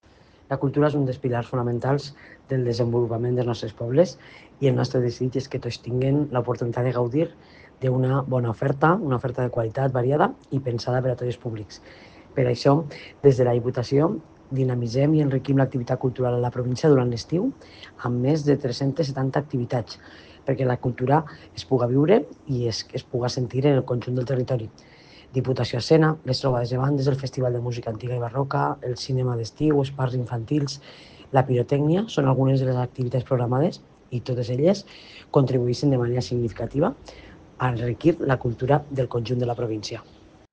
Presidenta-Marta-Barrachina-actividades-culturales-1.mp3